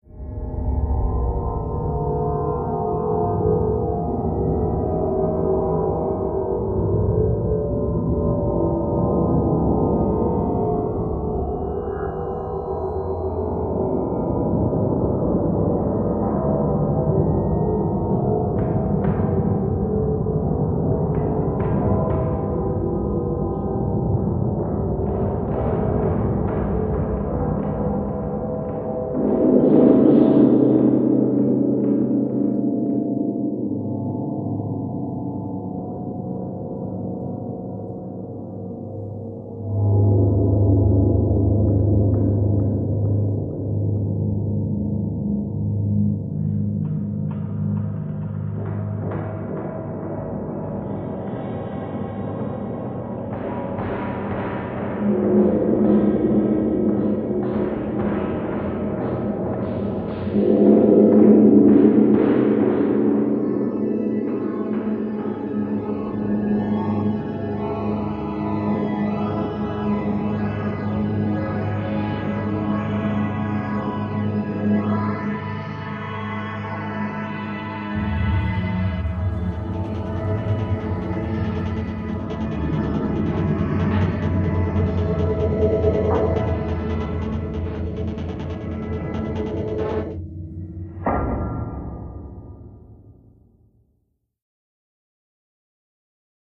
Cave Ambience with Sporadic Rhythmic Elements Cave, Rhythmic